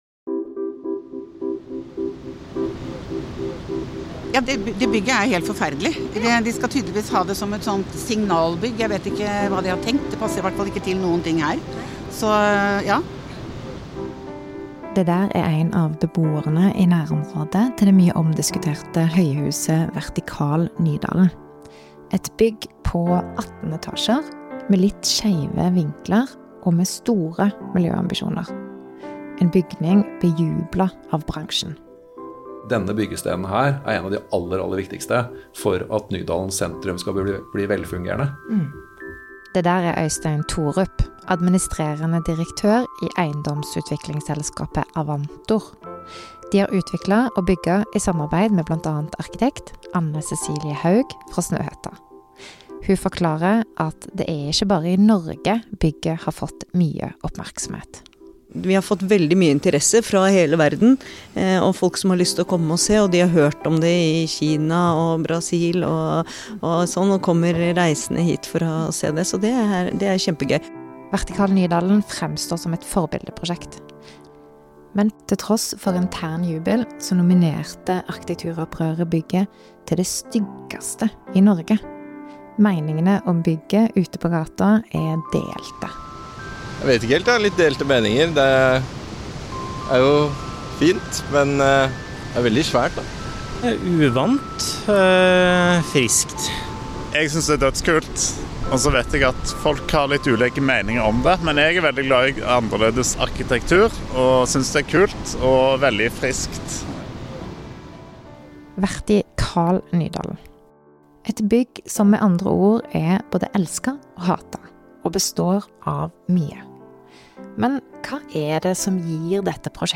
Du vil høre mer om førsteetasjene sin sentrale rolle, om naturlig ventilasjon og betydningen dette har for miljøet, om stedsmarkører og hvilken rolle de spiller i å skape et sted. Og ikke minst vil høre stemmene fra folk i Nydalen og hva de tenker om Vertikalen, og fra en som bor i bygget og hvordan hun opplever bokvaliteten i det som en gang ble nominert til Norges styggeste bygg.